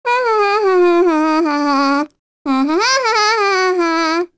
Sonia-vox-Hum.wav